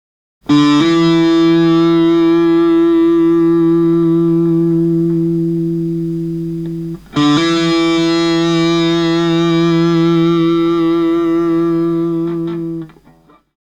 Hammer-on , záznam příklepu do tabulatury, zde z prázdné struny:
Hammer-on na kytaru
priklep.mp3